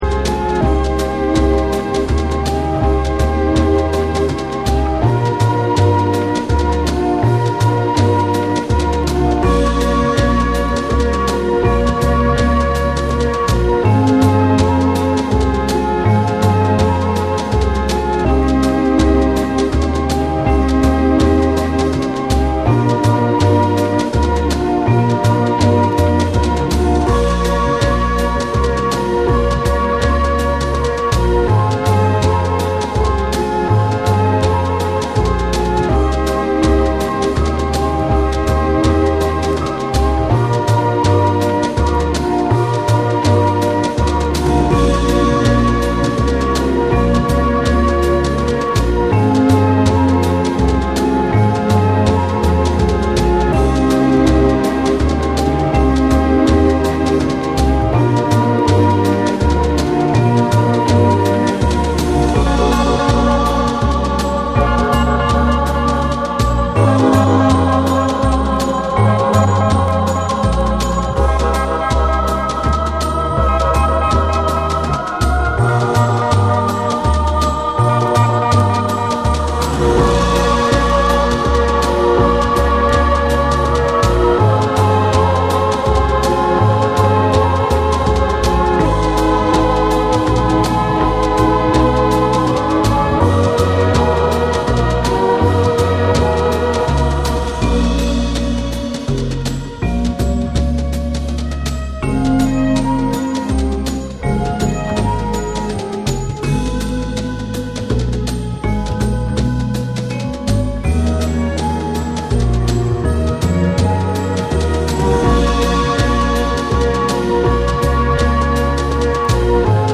サントラ/ライブラリー好きから、エレクトロニカ／ダウンテンポのリスナーまで幅広くおすすめ。
BREAKBEATS